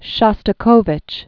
(shŏstə-kōvĭch, -kô-, shə-stə-), Dmitri 1906-1975.